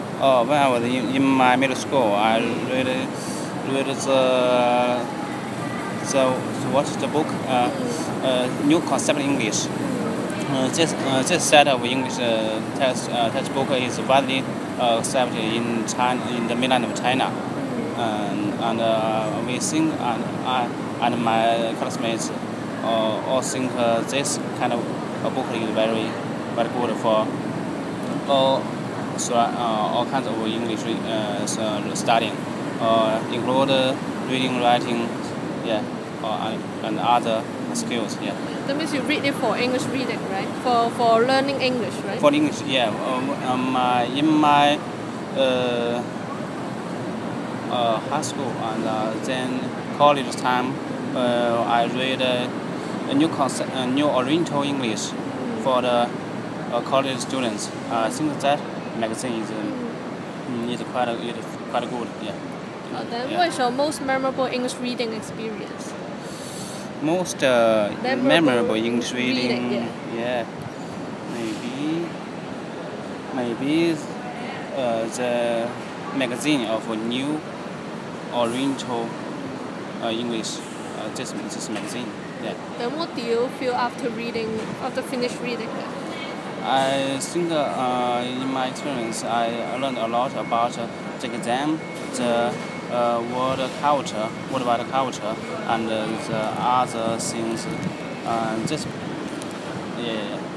Subcategory: Journalism, Non-fiction, Reading